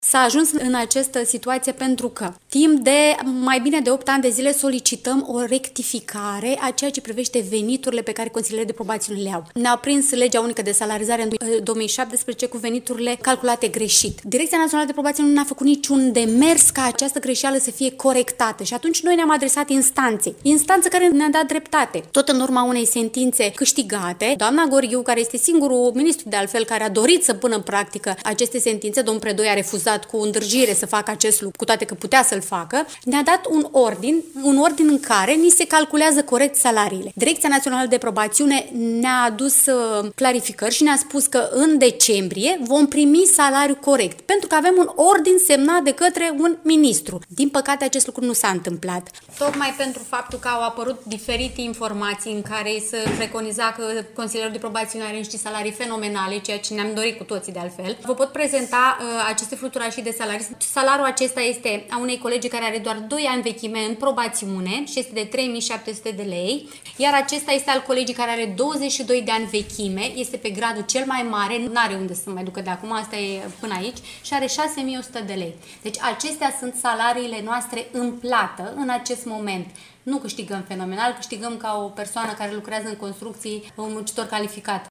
13-dec-rdj-17-vox-pop.mp3